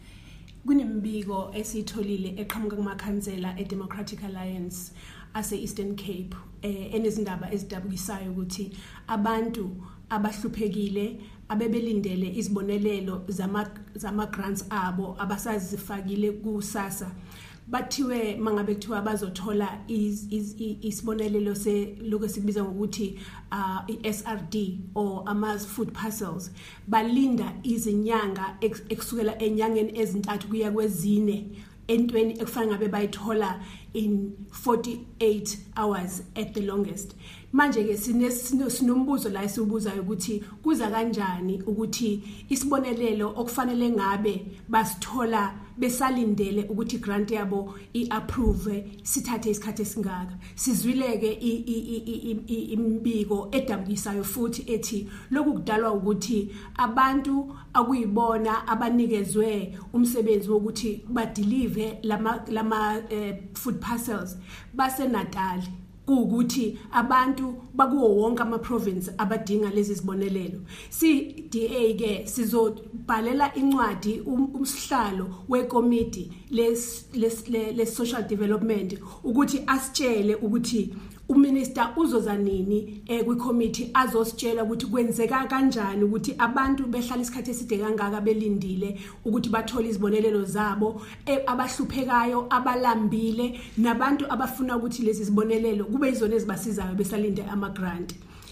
IsiZulu and English soundbites by the DA Shadow Minister of Social Development, Bridget Masango MP.